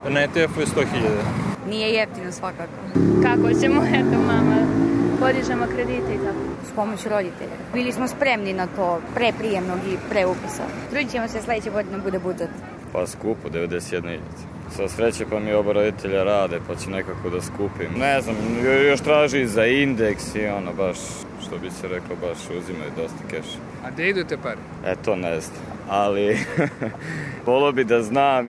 Studenti o troškovima studiranja